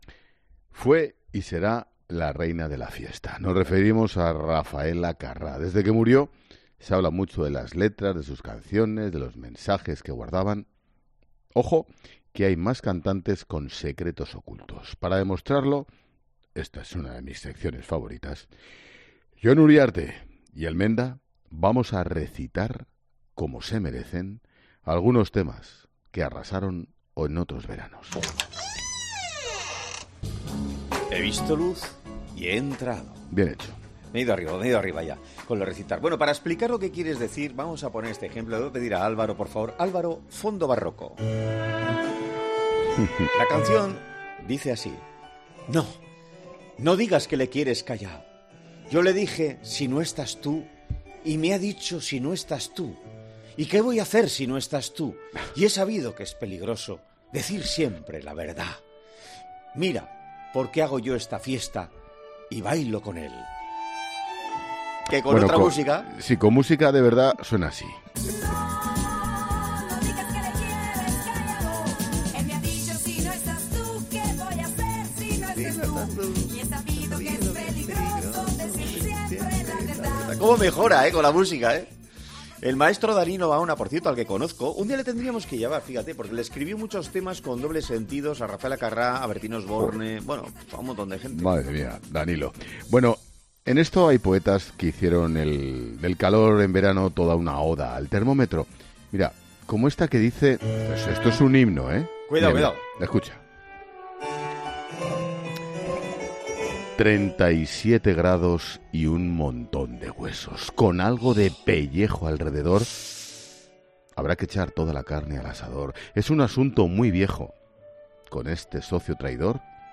Recitamos letras de canciones
Hoy hemos estado recitando a Carrá y otros éxitos del verano,